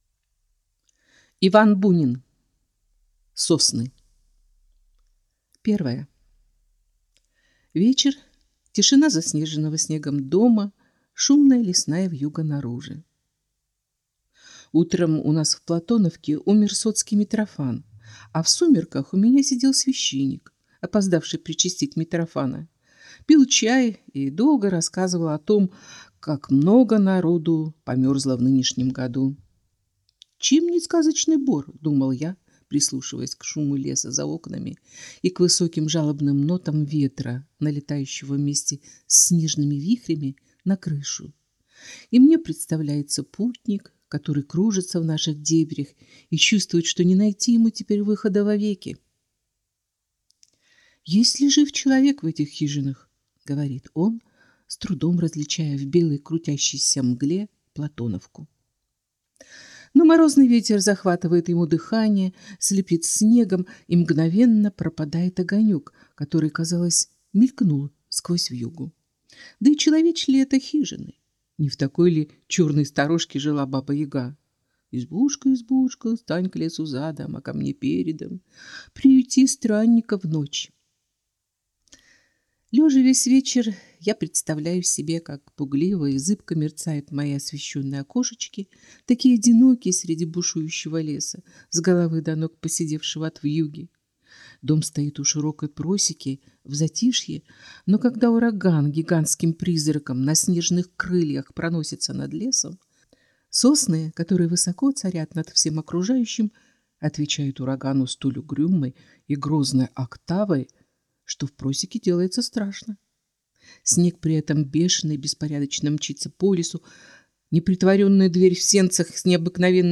Аудиокнига Сосны | Библиотека аудиокниг